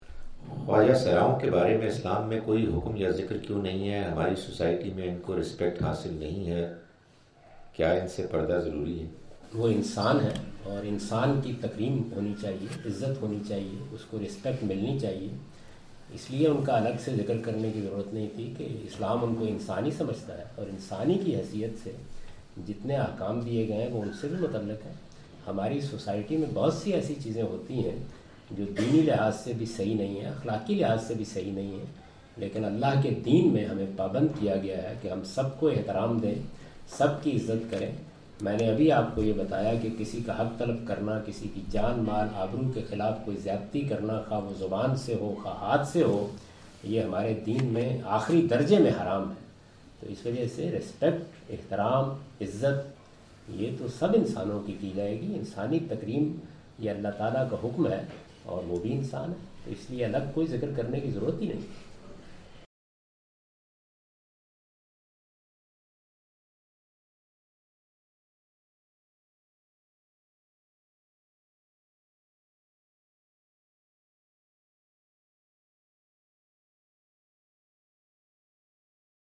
Javed Ahmad Ghamidi answer the question about "ruling of Islam regarding transgenders" during his visit to Manchester UK in March 06, 2016.
جاوید احمد صاحب غامدی اپنے دورہ برطانیہ 2016 کے دوران مانچسٹر میں "خواجہ سراؤں کے متعلق تعلیمات" سے متعلق ایک سوال کا جواب دے رہے ہیں۔